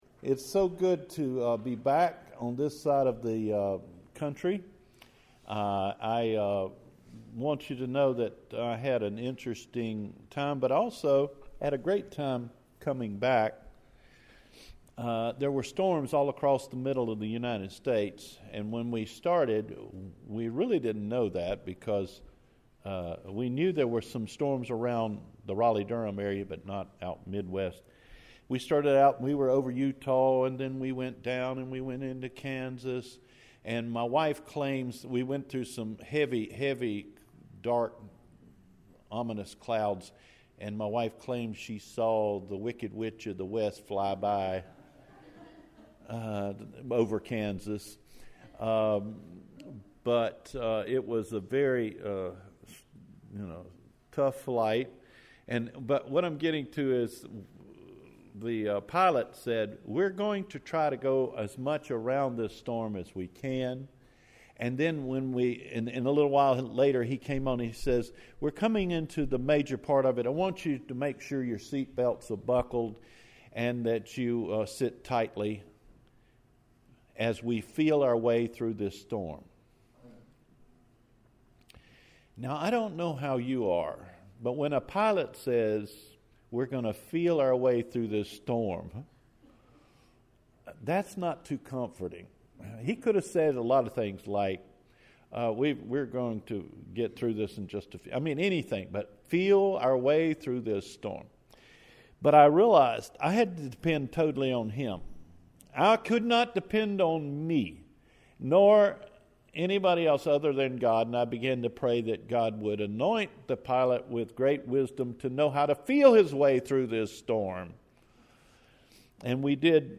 The Importance of Mentoring – June 3 Sermon
Cedarfork-sermon-June3-2018.mp3